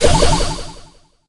throw_bawl_ulti_01.ogg